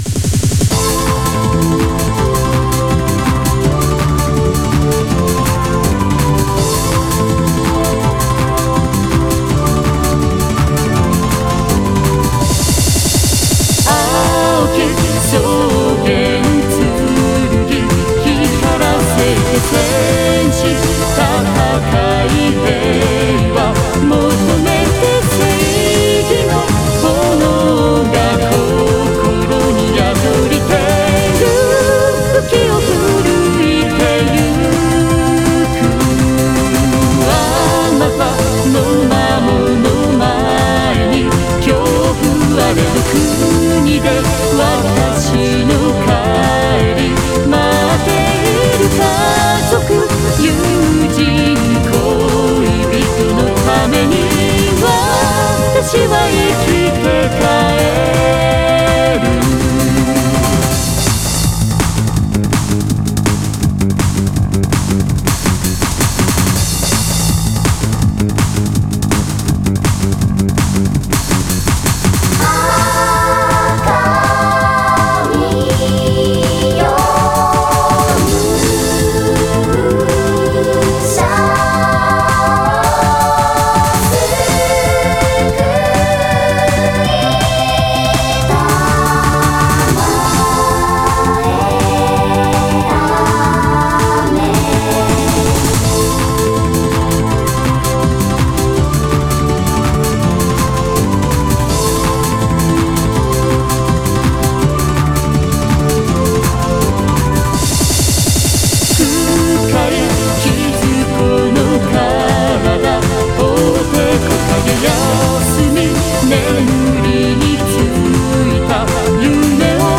私のオリジナル曲のうち、ゲームミュージック風の曲を公開いたします。
ラスボス曲のVocal Synthesizer版です。
VOCAL VERSION